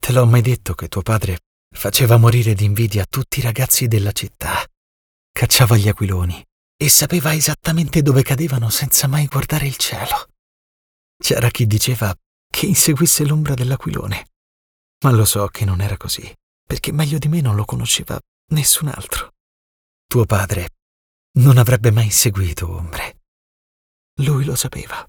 Male
Bright, Engaging, Friendly, Versatile, Authoritative, Character
Television Series Reality Show Dubbing -....mp3
Microphone: Neumann TLM 103, Universal Audio Sphere Dlx